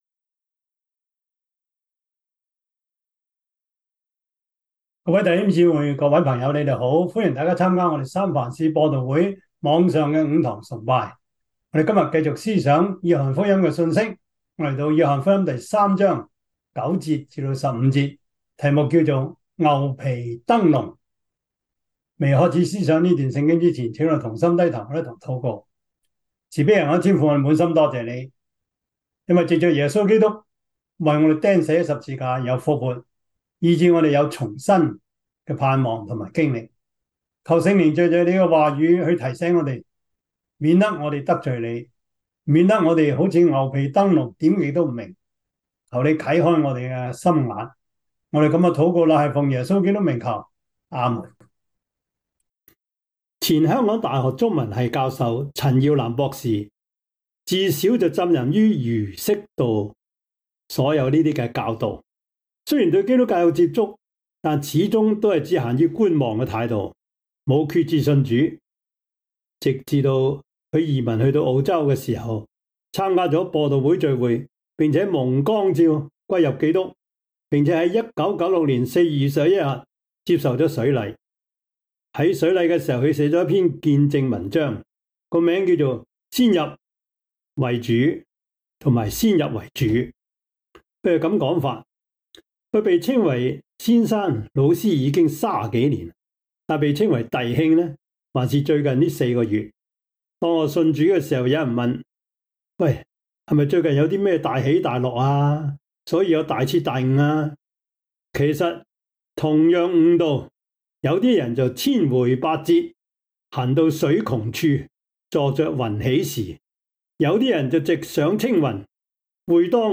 約翰福音 3:9-15 Service Type: 主日崇拜 約翰福音 3:9-15 Chinese Union Version
Topics: 主日證道 « 在群體中成長 基礎神學 (二) – 第四課 »